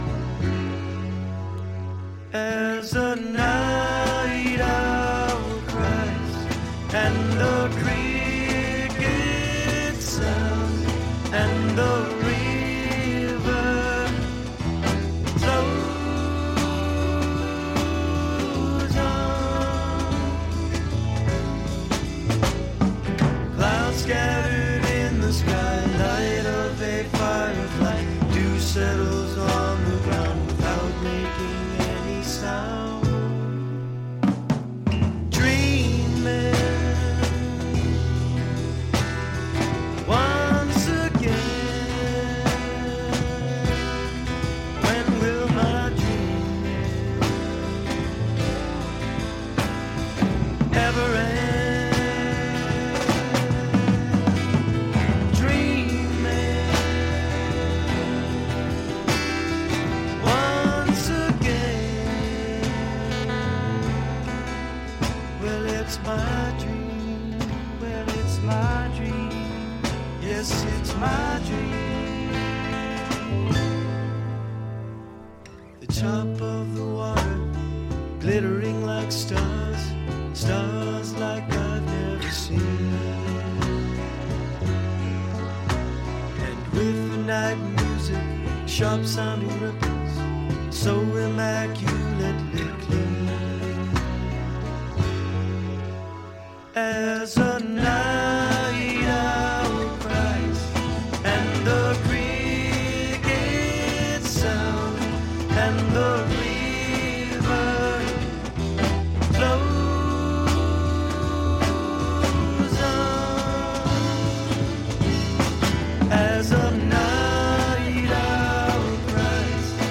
美しいメロディー、ハーモニーに思わず聴き入ってしまう傑作揃いです！